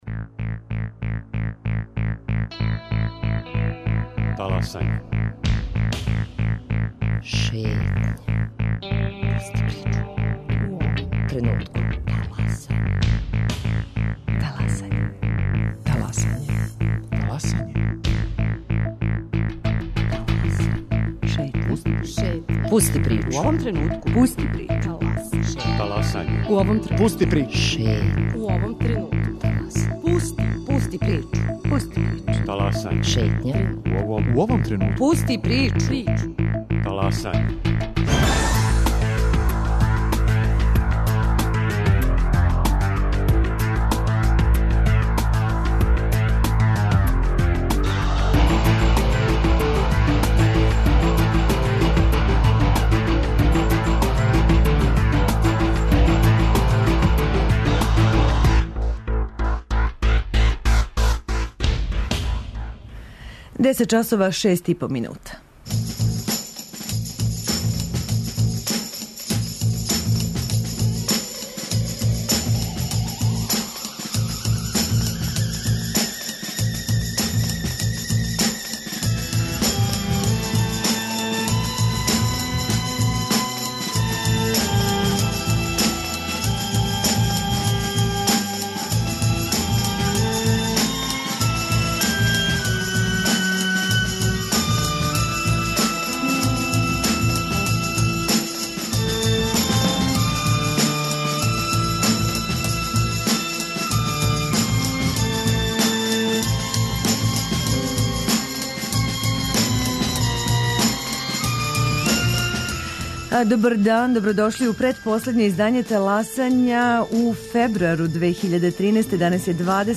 У сусрет премијери говоре актери представе која је узбуркала јавност и пре него што је ико био у прилици да је види, с обзиром на високу цену њене продукције у години која јесте година великог јубилеја не само наше културе, али која ће остати запамћена и као "најмршавија" по проценту издвојеном за културу из годишњег буџета државе.